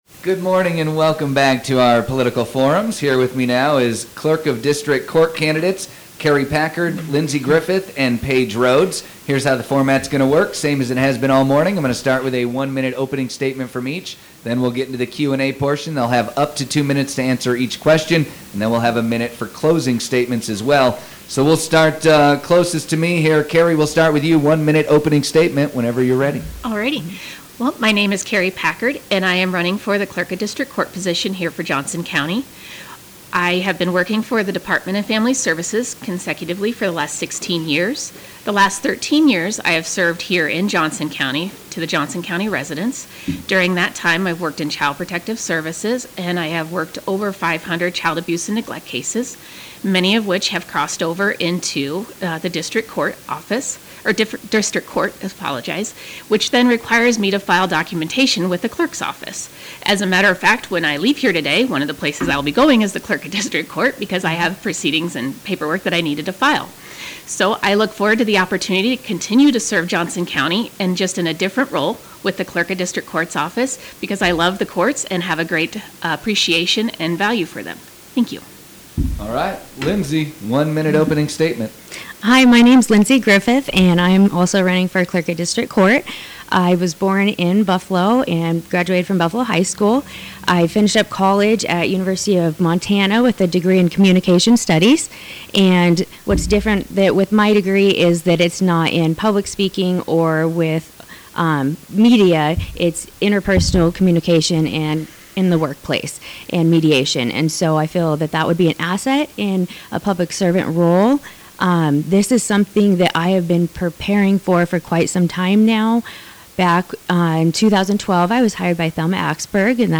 KBBS recently hosted a political forum for the Clerk of District Court candidates.